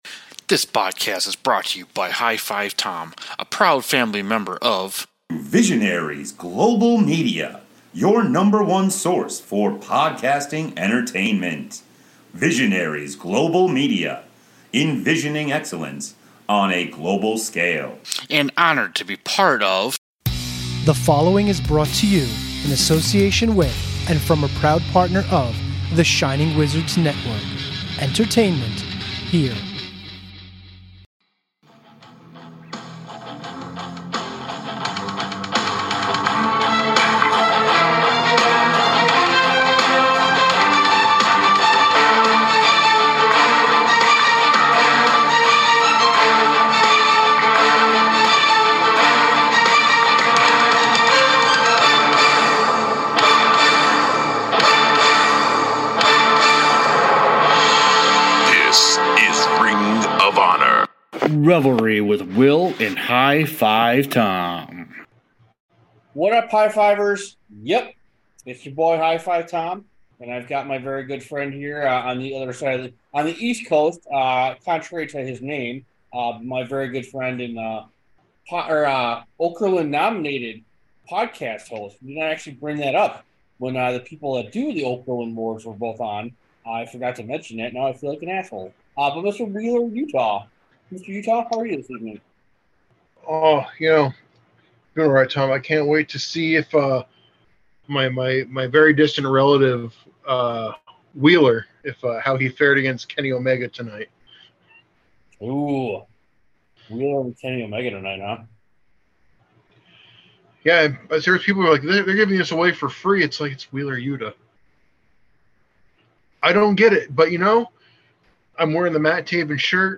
Also we get caught on some great Questions of Dishono(u)r. Also some technical difficulty so we appreciate you powering thru those Make sure to follow us on all the socials and our podcast families and partners below.